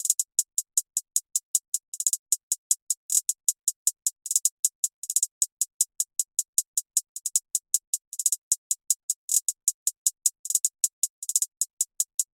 陷阱鼓循环2 仅限帽子
描述：一个155 BPM的陷阱鼓乐，只有hihats。
Tag: 155 bpm Trap Loops Percussion Loops 2.08 MB wav Key : Unknown